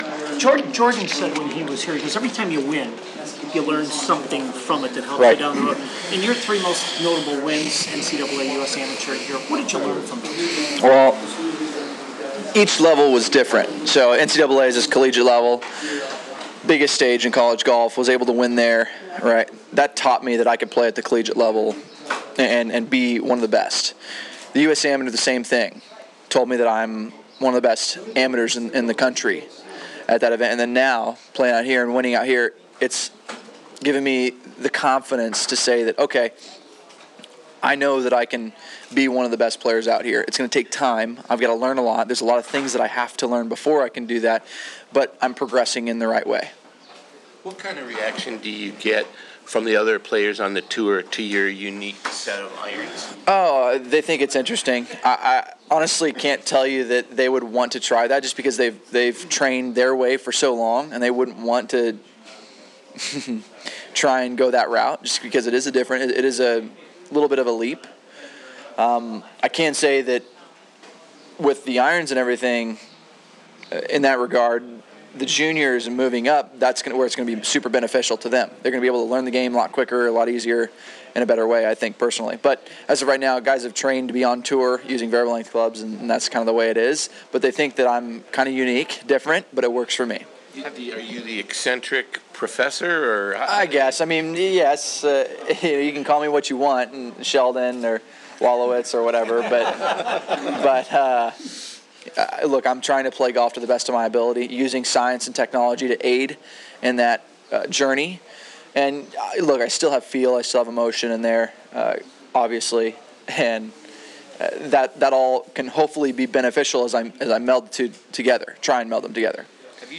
Defending JDC Champion Bryson DeChambeau talks with the media on the patio of TPC Deere Run on media day in May.
JDC-Bryson-DeChambeau-outside-clubhouse-scrum.mp3